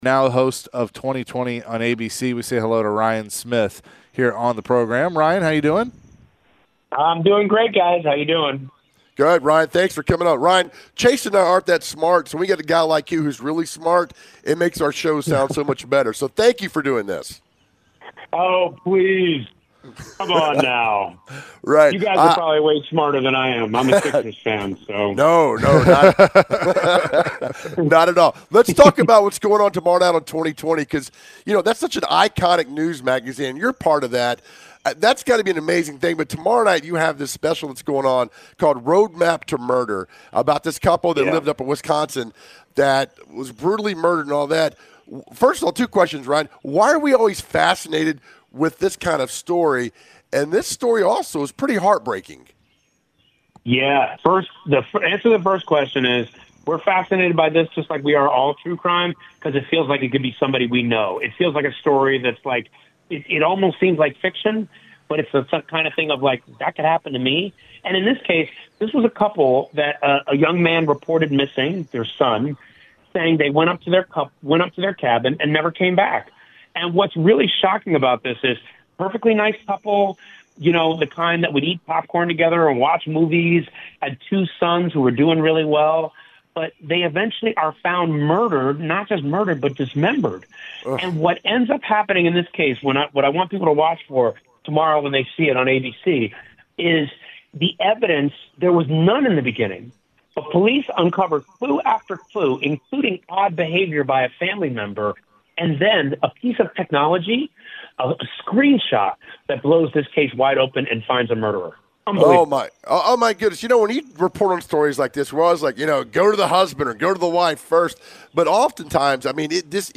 The guys chatted with ESPN/ABC host and investigative reporter Ryan Smith joined the show. Ryan spoke on his new story coming out on 20/20. Later in the conversation, Ryan mentioned his experience with investigative reporting on the Steve McNair murder case.